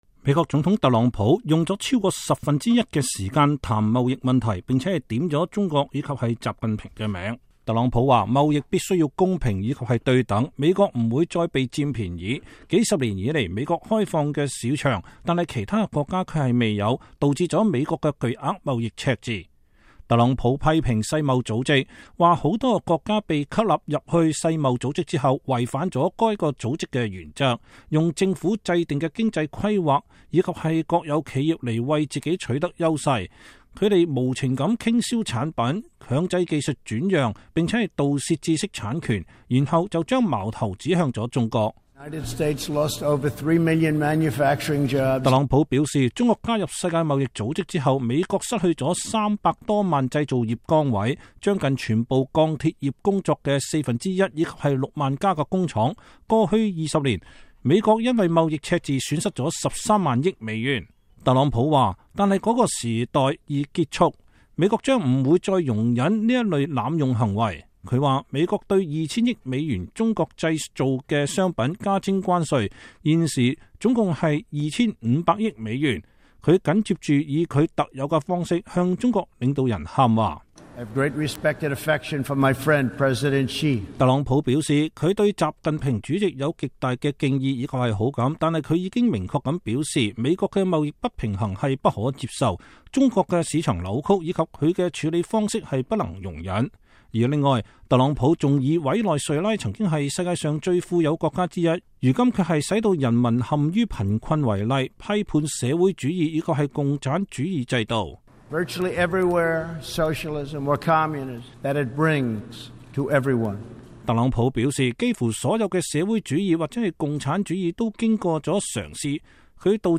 美國總統特朗普星期二上午在聯合國大會一般性辯論中發表演講。